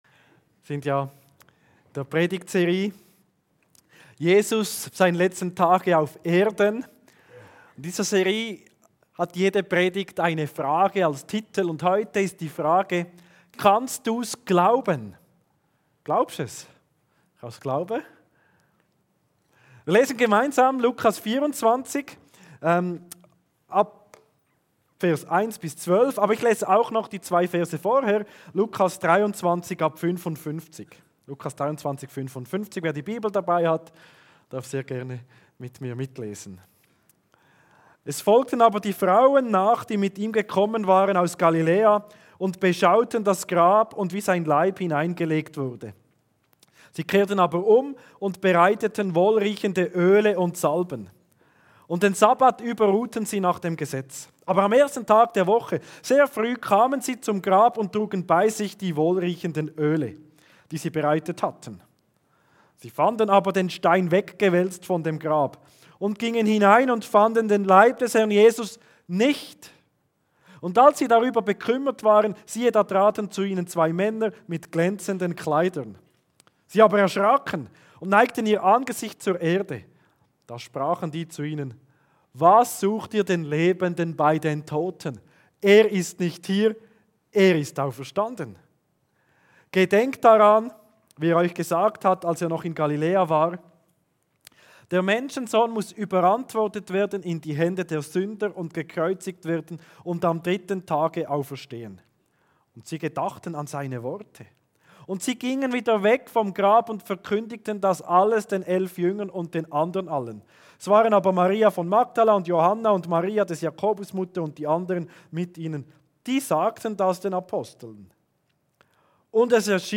Kategorie: Predigt